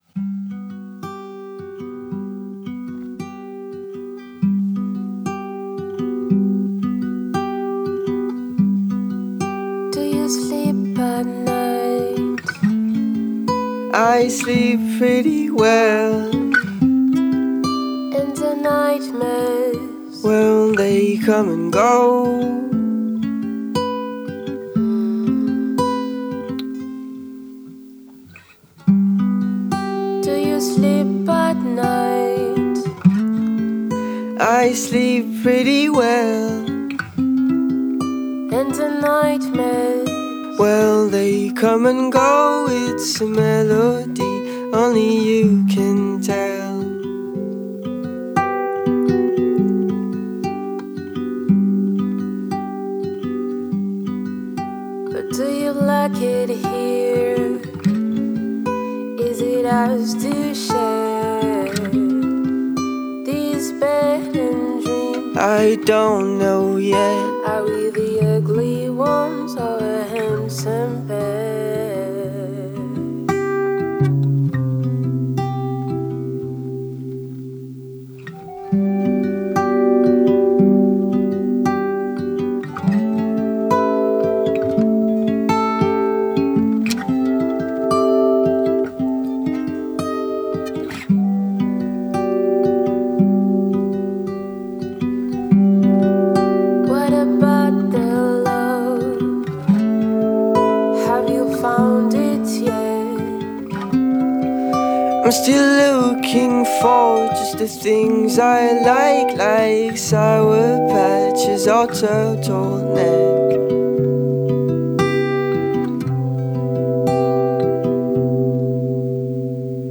folk matinée de pop et de swing et finement arrangée